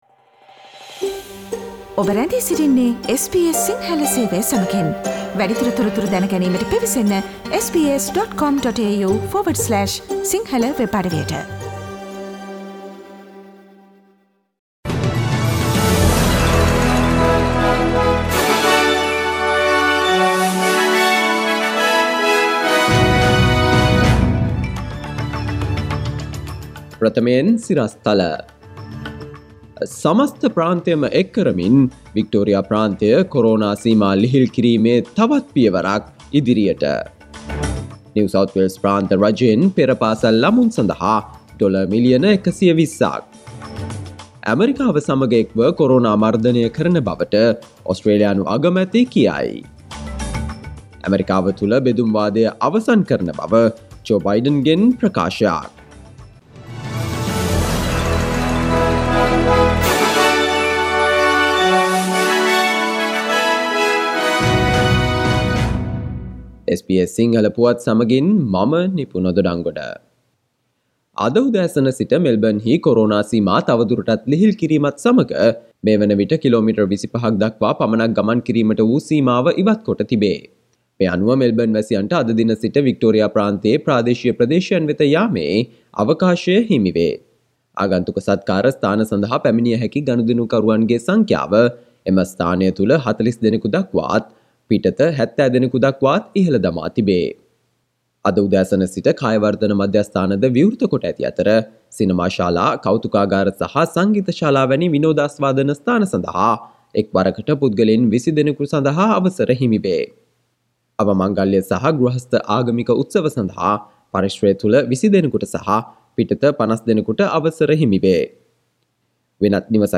Daily News bulletin of SBS Sinhala Service: Monday 09 November 2020
Today’s news bulletin of SBS Sinhala Radio – Monday 09 November 2020 Listen to SBS Sinhala Radio on Monday, Tuesday, Thursday, and Friday between 11 am to 12 noon